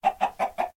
chicken_say2.ogg